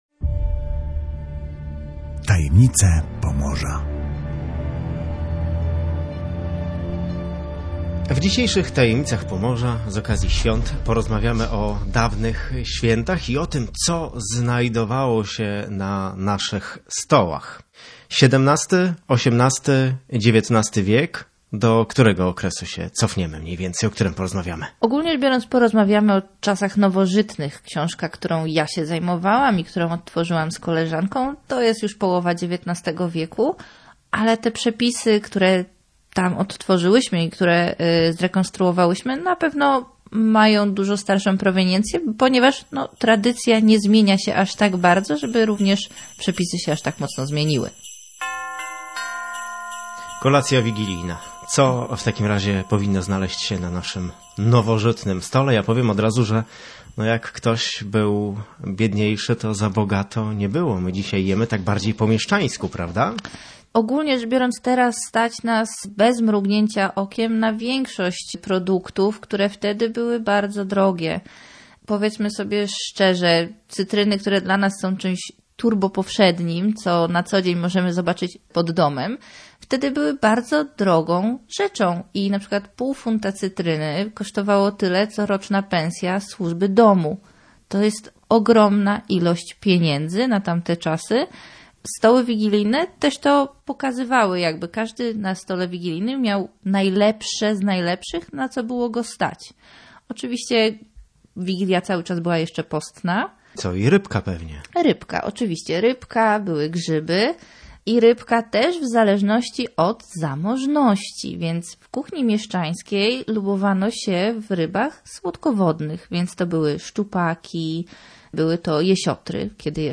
Opowiada muzealniczka